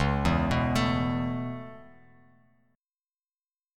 Dbm7#5 chord